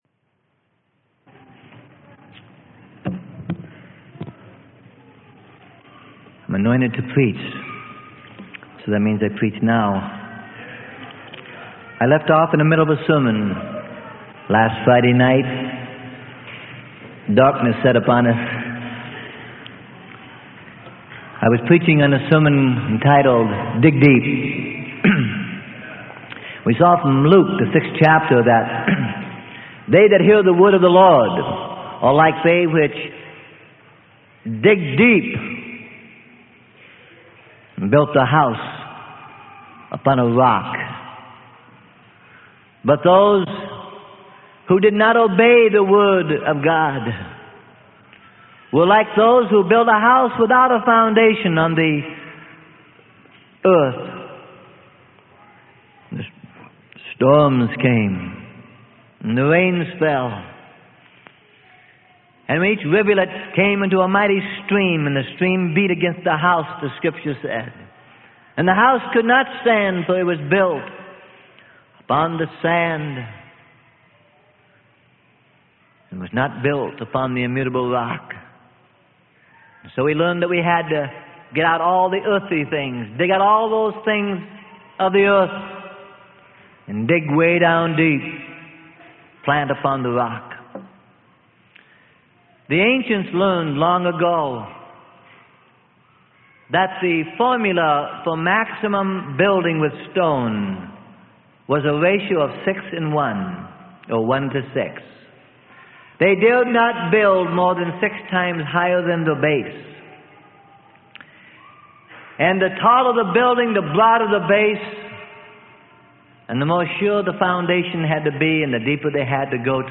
Sermon: Dig Deep - Part 2 Of 2 * - Freely Given Online Library